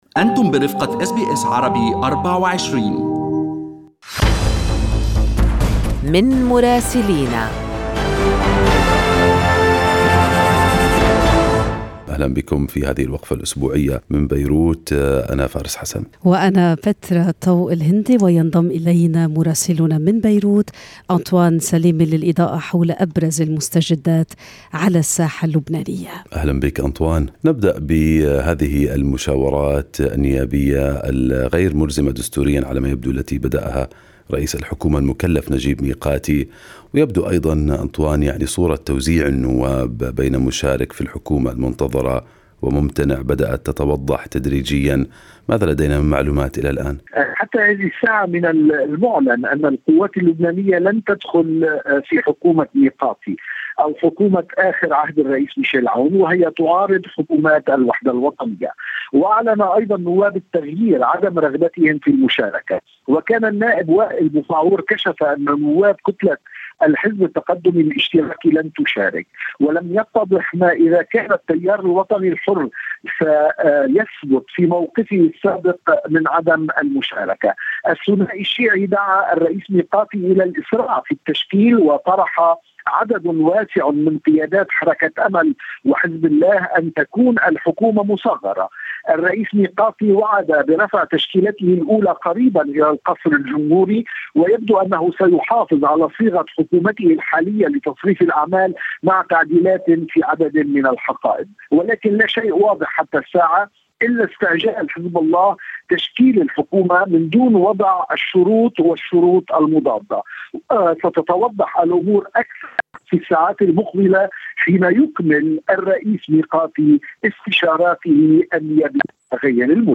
يمكنكم الاستماع إلى تقرير مراسلنا في لبنان بالضغط على التسجيل الصوتي أعلاه.